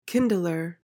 PRONUNCIATION:
(KIN-duh-luhr)